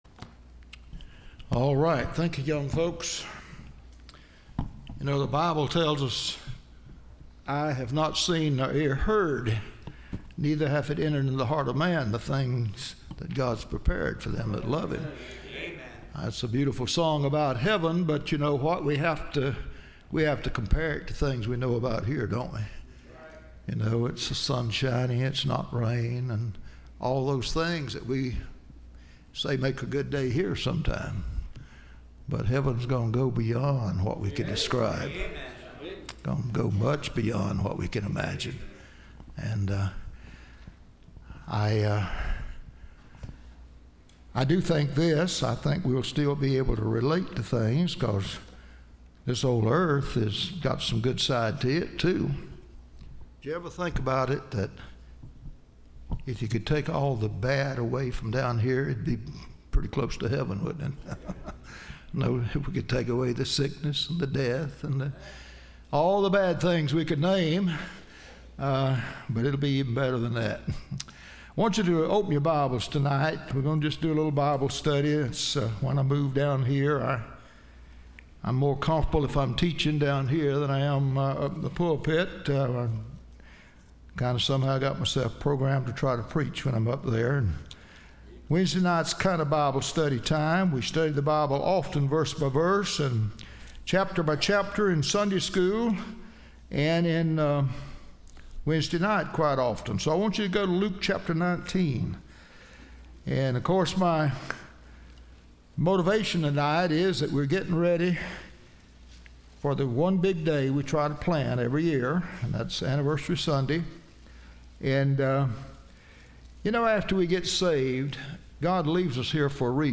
Listen to Message
Service Type: Wednesday